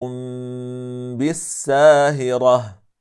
Det ska uttalas på följande sätt:                  Det ska uttalas på följande sätt: